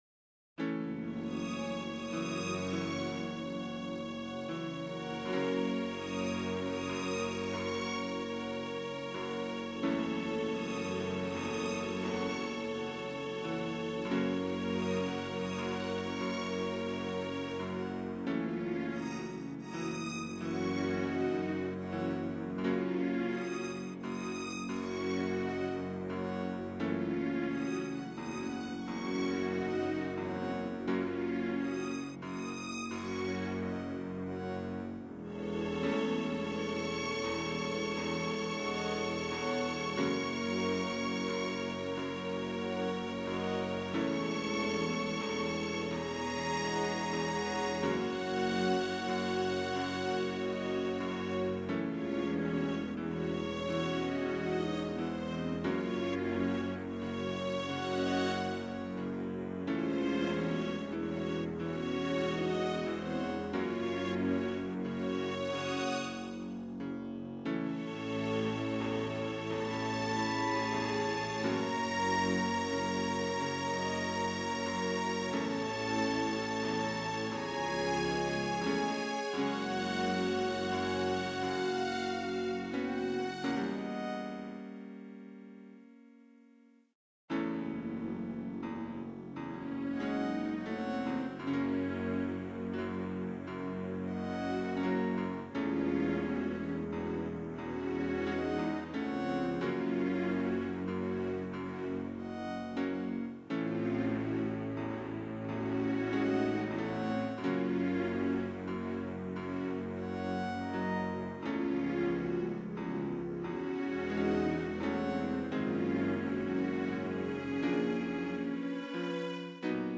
Slow Strings Melody for a change of pace